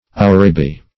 Search Result for " ourebi" : The Collaborative International Dictionary of English v.0.48: Ourebi \Ou"re*bi\, n. (Zool.) A small, graceful, and swift African antelope, allied to the klipspringer.